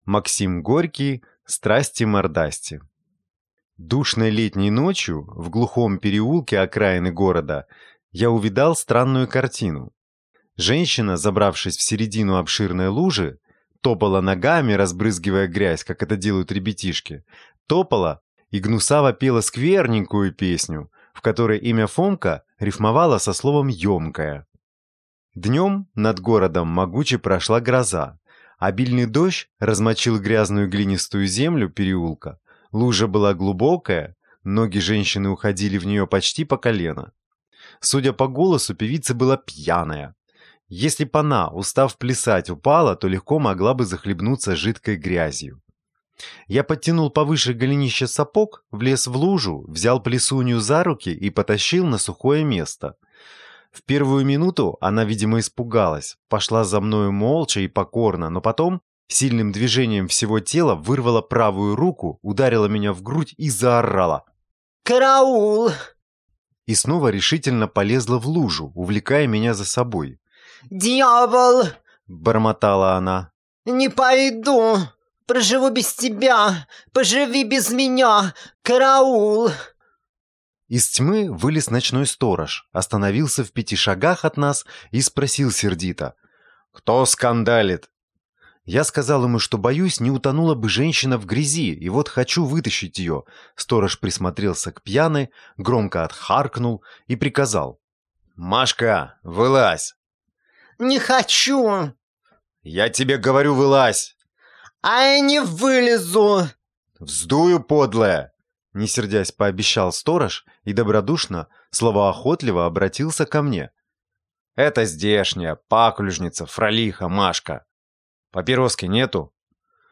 Аудиокнига «Страсти-мордасти» | Библиотека аудиокниг